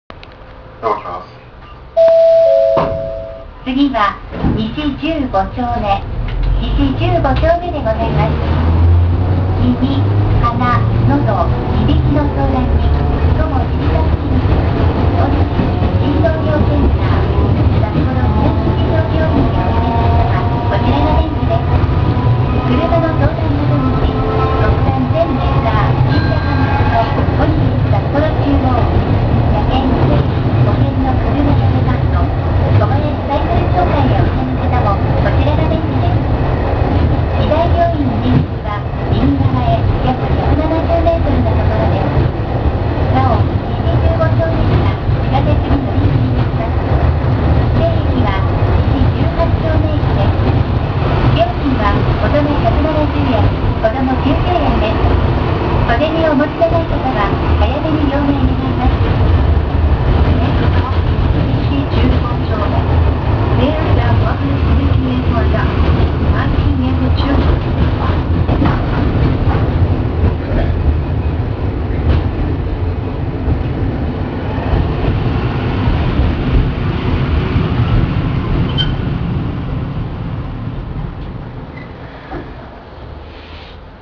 ・250形走行音
【一条線】中央区役所前→西15丁目…途中の信号まで。（1分24秒：457KB）
見た目からして当然ではありますが、吊り掛け式です。煩いものの、一般の鉄道線用車両の吊り掛け式と比べると大分軽い音。車内放送をかき消すほどの物ではありません。